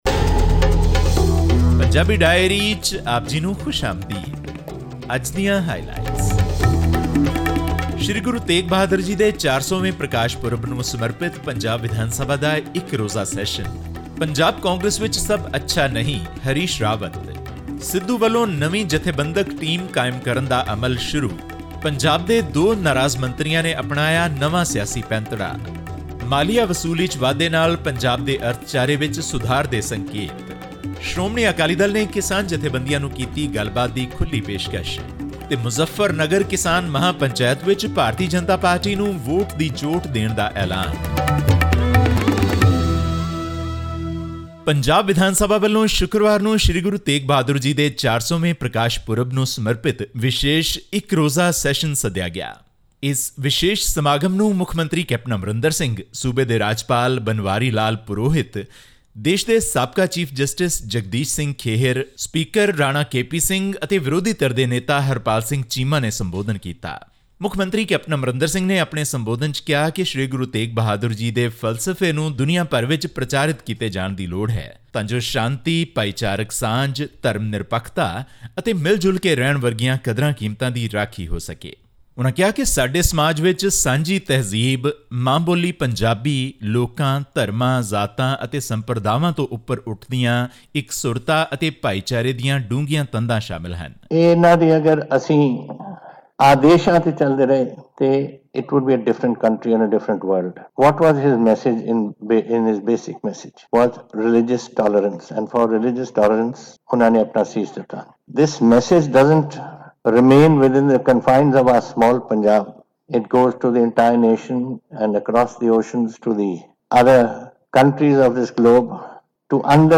Amid the ongoing infighting within Punjab Congress, the party affairs’ state in-charge Harish Rawat admitted that there were still some issues within the party that are being resolved. This and more in our weekly news bulletin from Punjab.